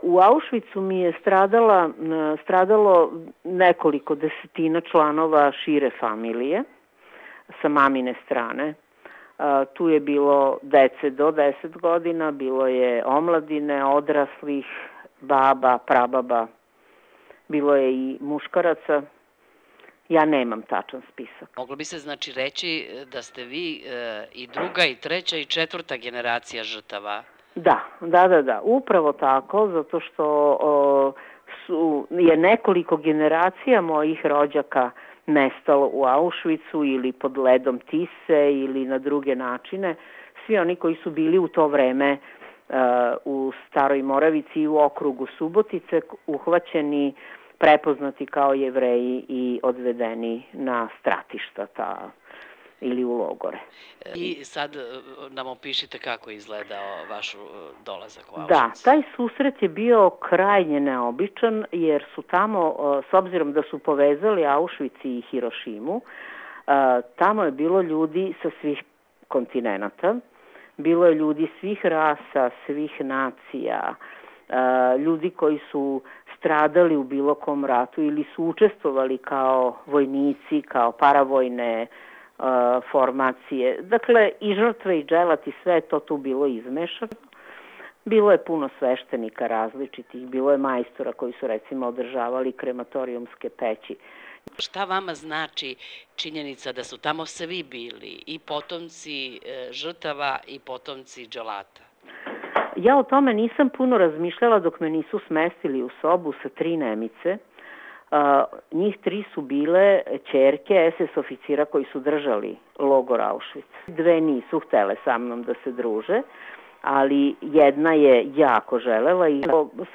Razgovor